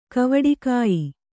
♪ kavaḍikāyi